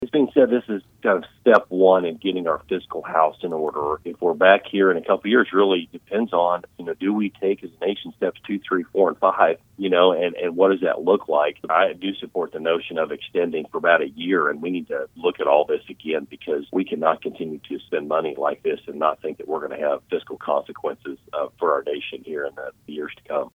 Kansas 1st District Congressman Tracey Mann joined KMAN’s Morning News Tuesday, and says the deal is a mixed bag.